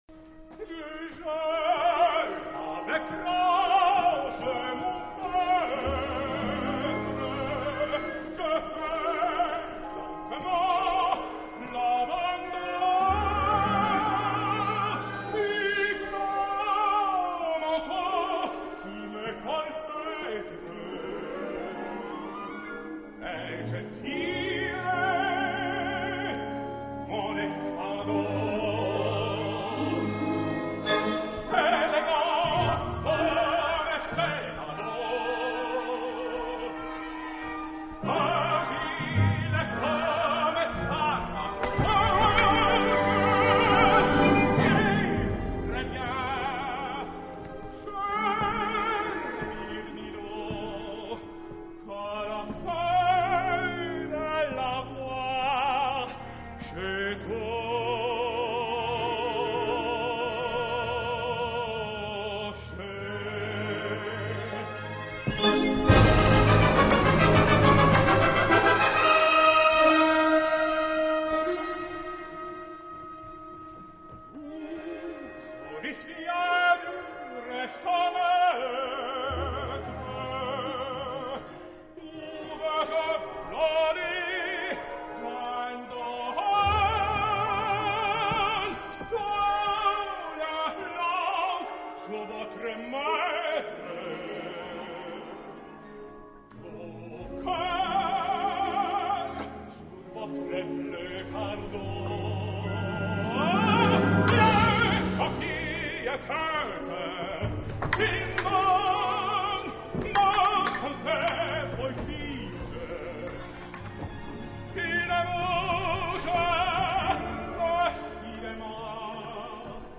französisch / french
Cirano di Bergerac [Tenor]